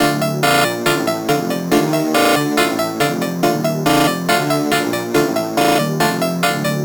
Gamer World Melody Loop 1.wav